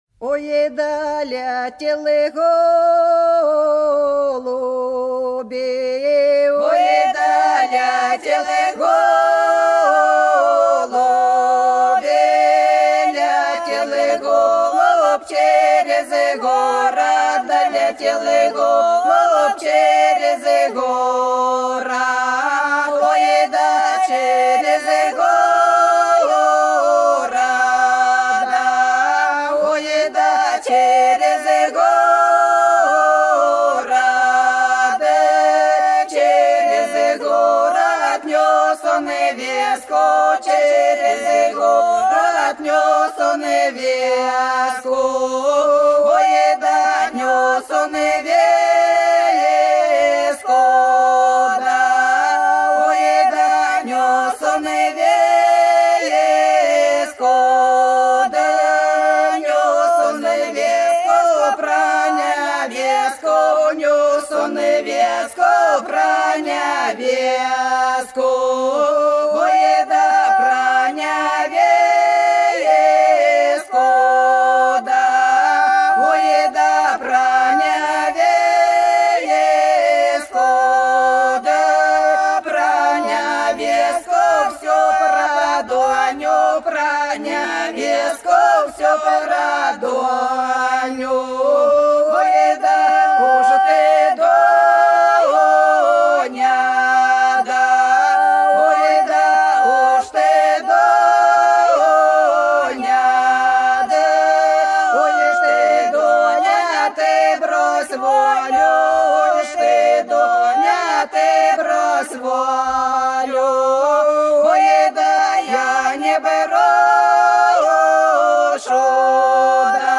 Долина была широкая (Поют народные исполнители села Нижняя Покровка Белгородской области) Ох, да летел голубь - хороводная